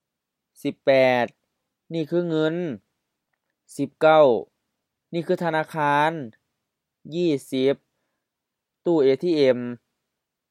Basic vocabulary — Page 23 — Series A, pictures 18-20: money, bank, ATM
เงิน ŋən HR เงิน money
ทะนาคาน tha-na:-kha:n H-HR-HR ธนาคาร bank